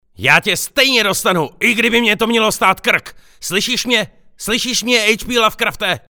Profesionální dabing - mužský hlas